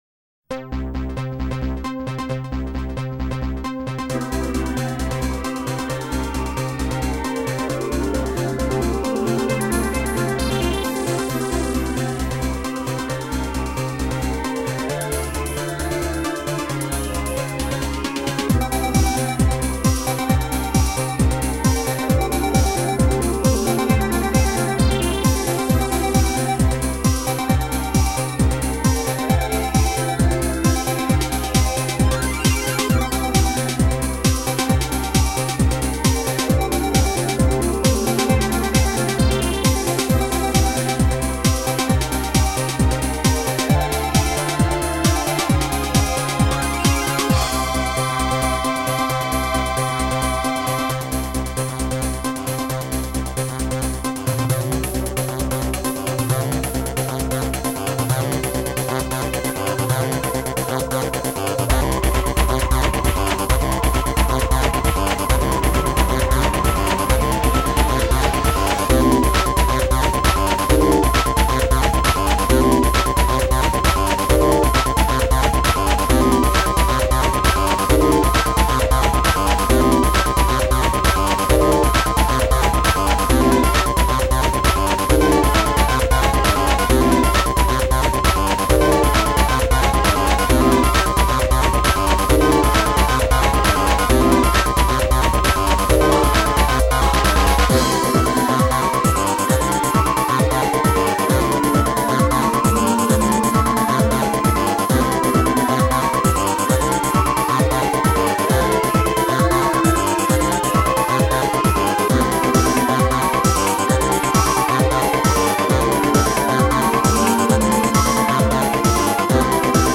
version arcade